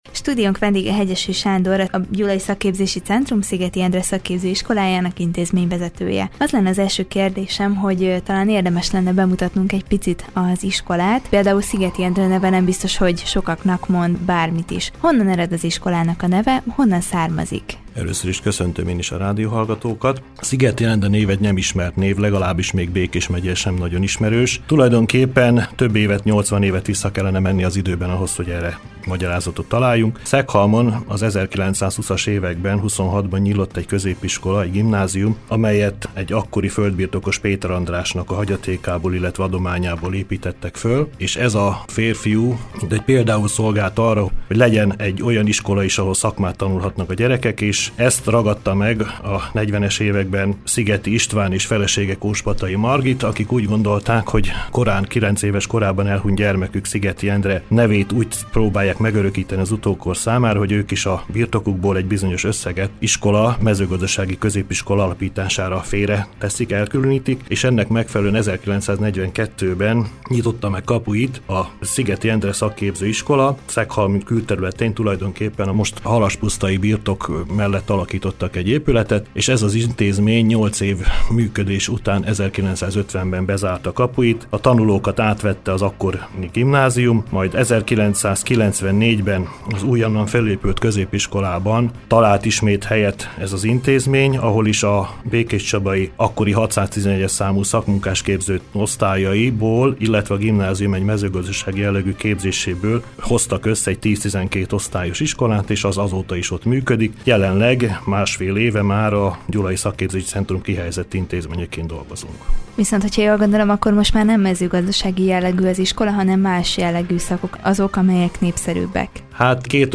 Vele beszélgetett tudósítónk az iskola történetéről, névadójáról és a képzésekről.